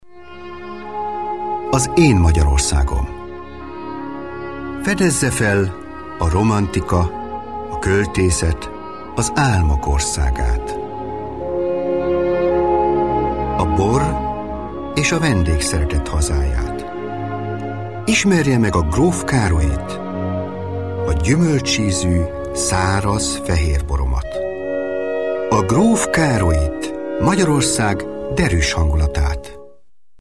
ungarischer Profi Sprecher Ausbildung: Hochschulstudium in Ungarn (Philologie) Tätigkeiten: Fremdsprachenredakteur, Sprecher, Regisseur, Moderator, Übersetzer mit journalisticher Textbearbeitung und eingetragener Dolmetscher Referenzenauszug: Allianz Versicherungen, BASF, Deutsche Welle, Ford, Hösch, KHD, Langenscheidt KG, Opel, Paul Hartmann AG, Samsung, Sony und WDR Arbeitsgebiet: Deutschland, Belgien, Niederlande, Schweiz Italien und Ungarn Produktionen: Trailer, Imagefilm, TV Film, TV Werbung, Funkwerbung, Dokumentarfilm, Lehrfilm, Hörbuch
Sprechprobe: Industrie (Muttersprache):
hungarian voice over artist